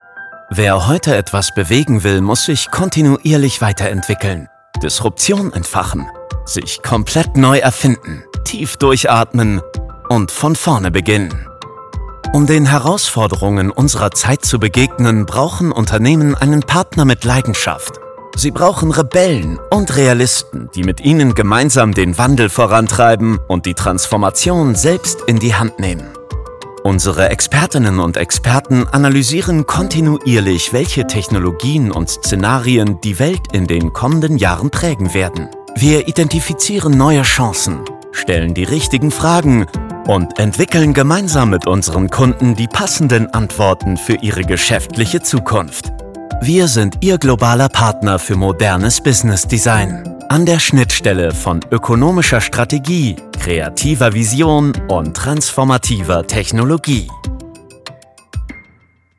dunkel, sonor, souverän, markant, sehr variabel
Mittel minus (25-45)
Imagefilm - modern & zuversichtlich
Commercial (Werbung), Presentation, Off, Narrative